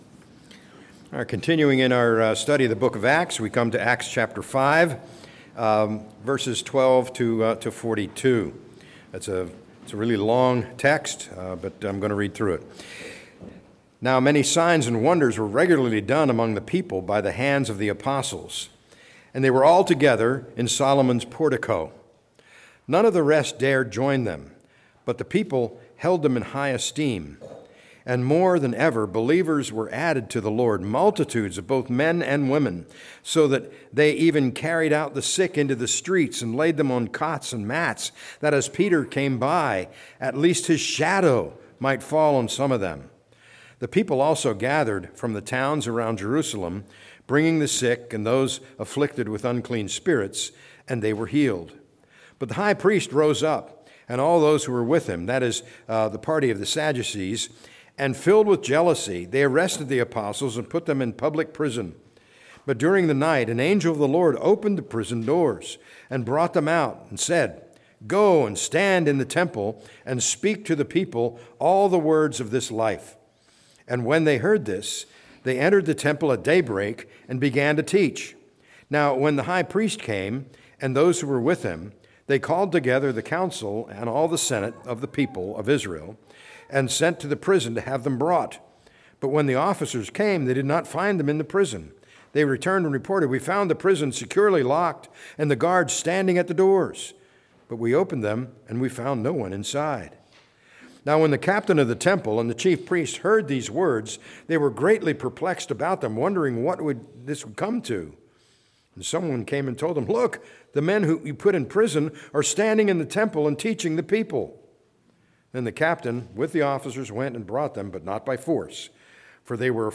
A message from the series "Act 2025."